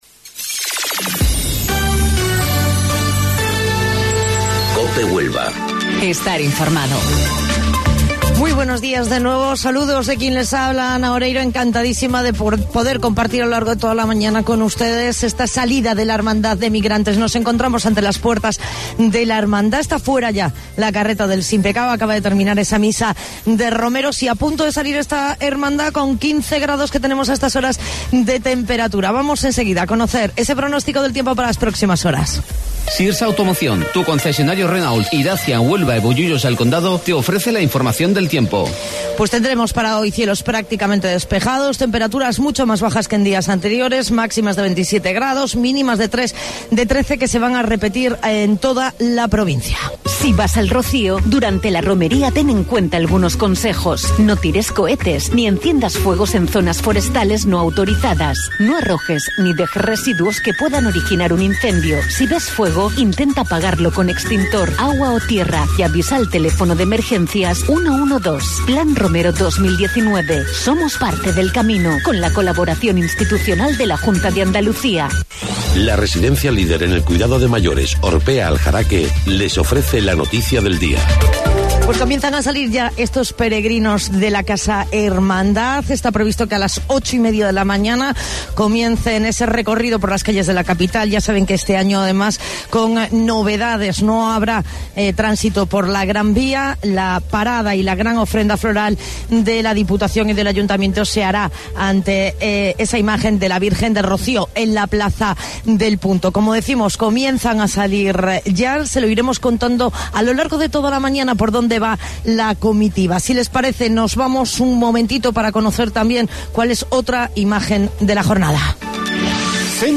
AUDIO: Informativo Local 08:25 del 5 de Junio